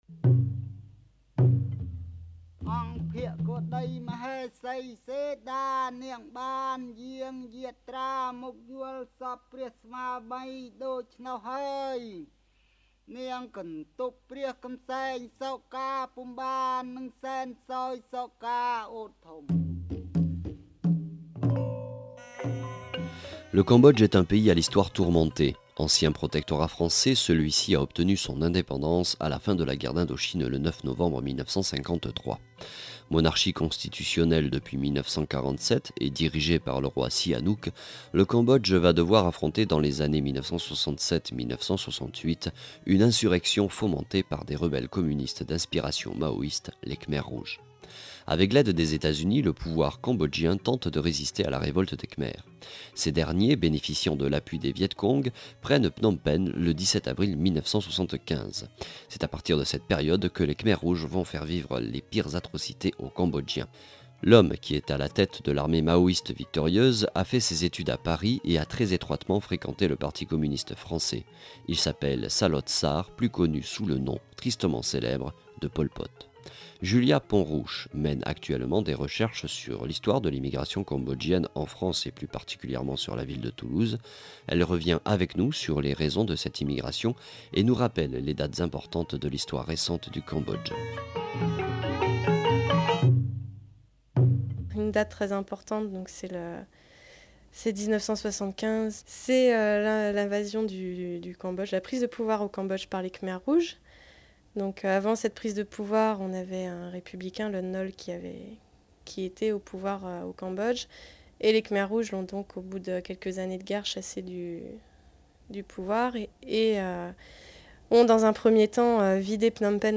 Entretien en deux parties.